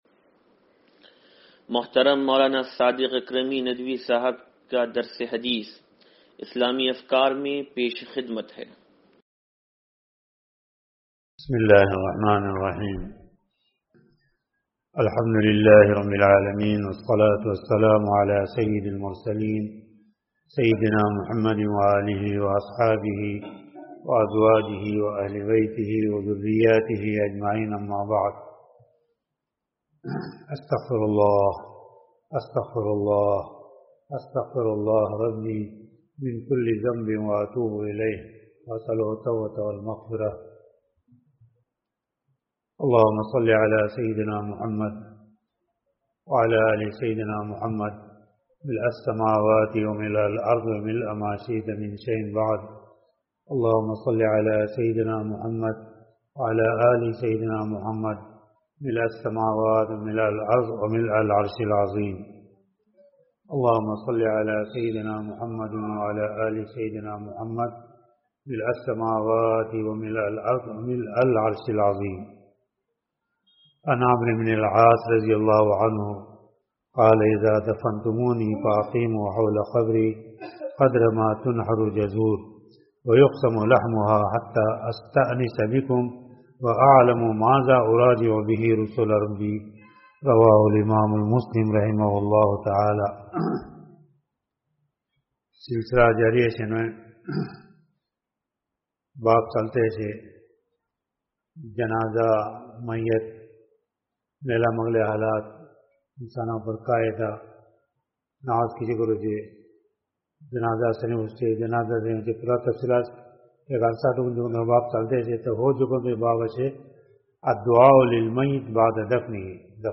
درس حدیث نمبر 0743